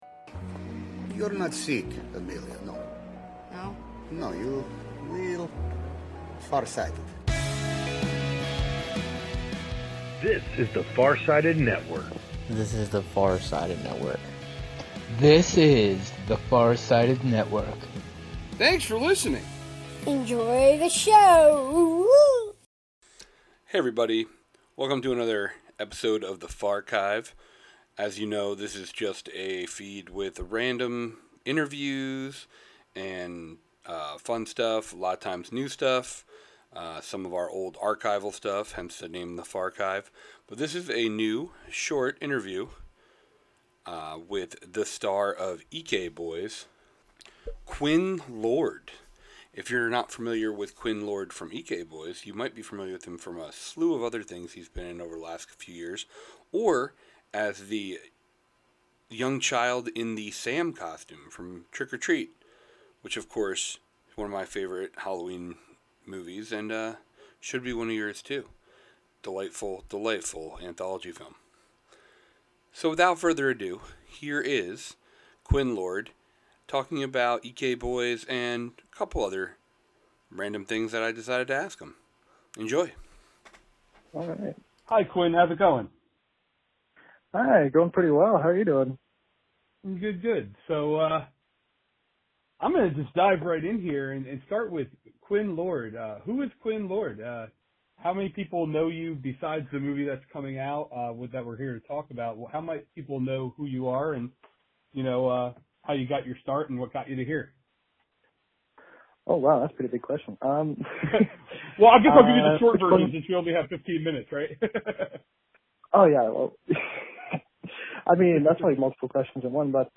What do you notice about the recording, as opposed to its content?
The rebranded pod features raw and (mostly) unedited interviews, both new and not-so-new.